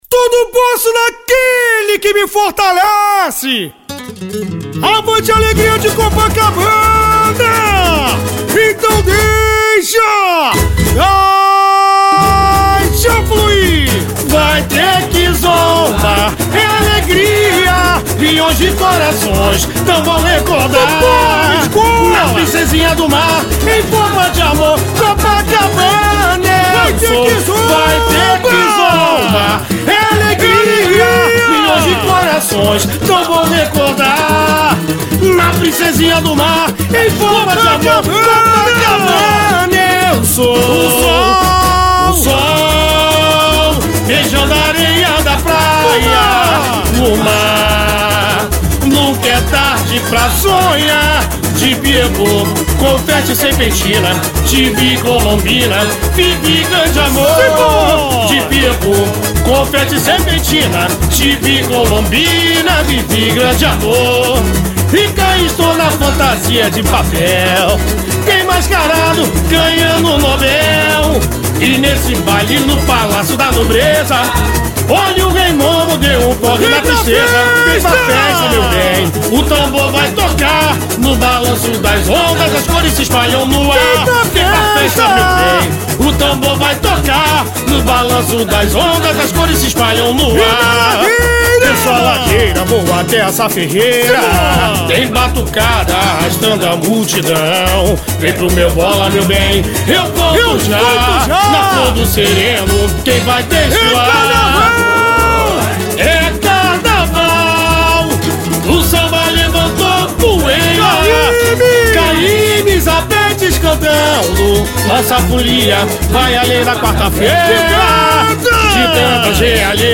Alegria de Copacabana define samba para Carnaval 2025